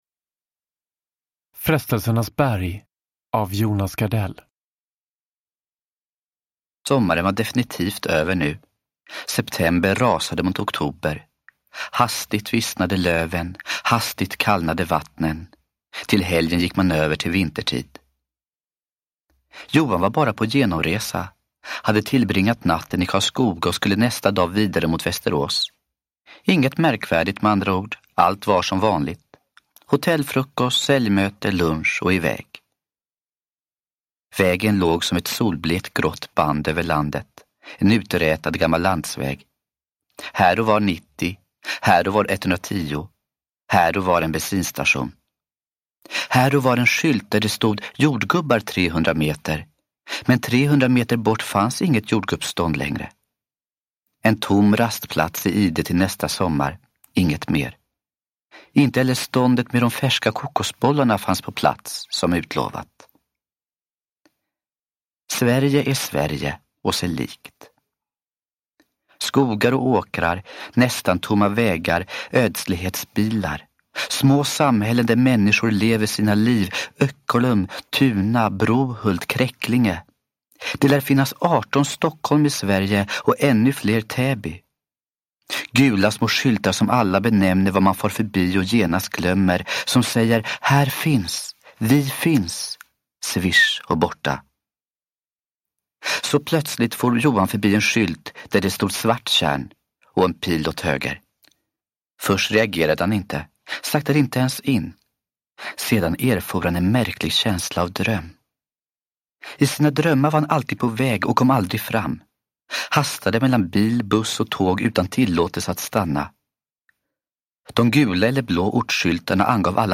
Frestelsernas berg – Ljudbok – Laddas ner
Frestelsernas berg är en starkt gripande skildring av en övergiven kvinna och en splittrad familj. Originalinspelningen gjordes 1995.
Uppläsare: Jonas Gardell